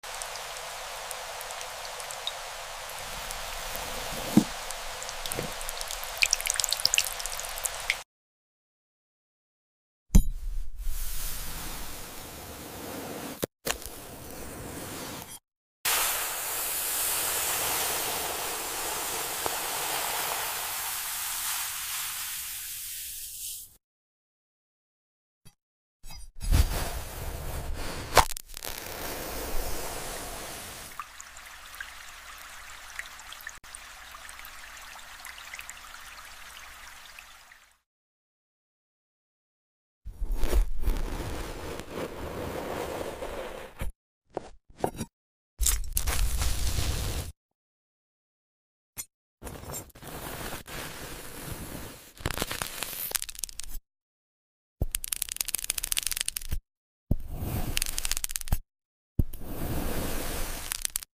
Cloud Cutting Asmr. Storm Clouds,